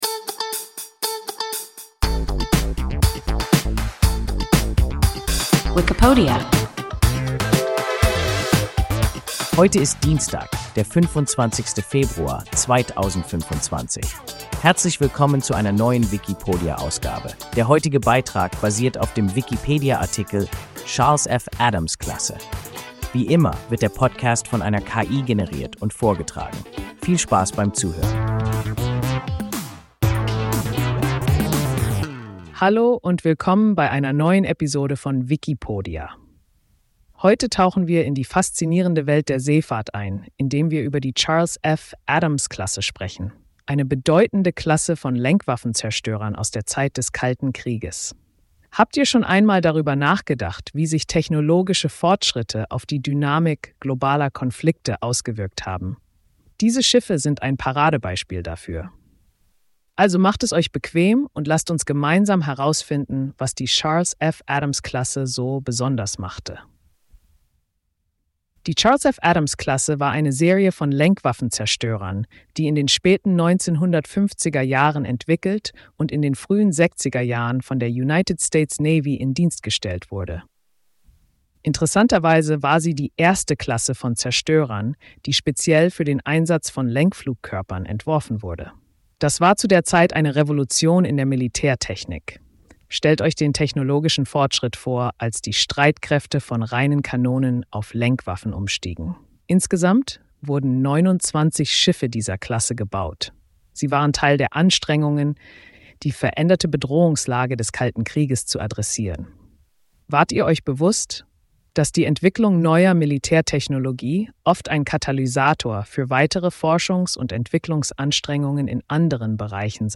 Charles-F.-Adams-Klasse – WIKIPODIA – ein KI Podcast